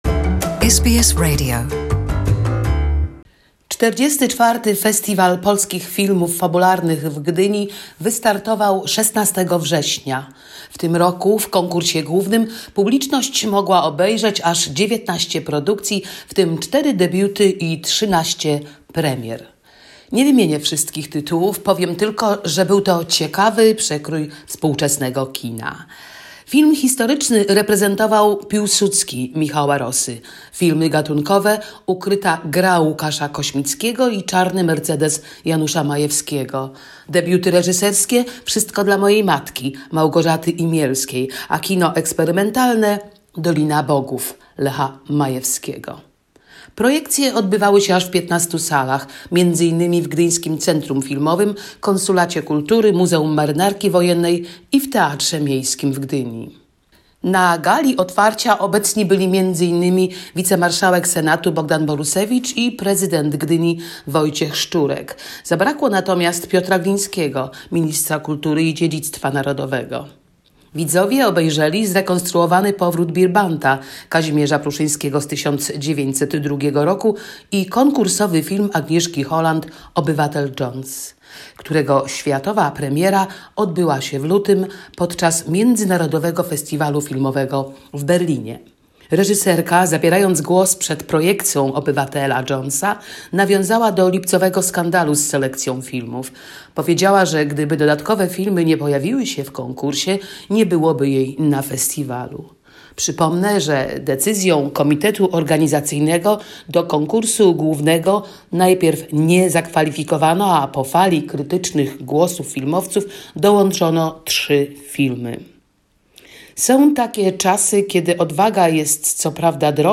reporting from the 44th Polish Film Festival in Gdynia.